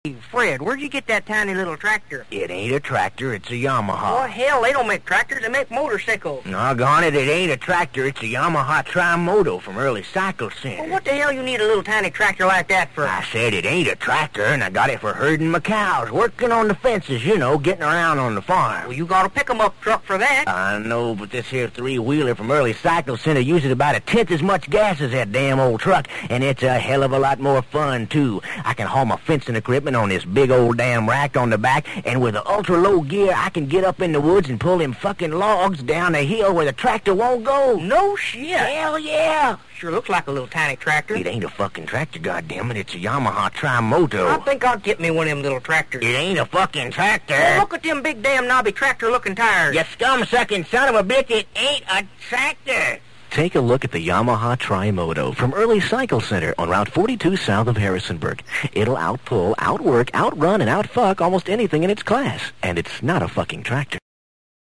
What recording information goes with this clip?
Turns out there is an Early’s Cycle Center still in business in Harrisonburg, VA, so I think we can assume it was made around there, probably by a local radio station.